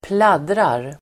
Uttal: [²pl'ad:rar]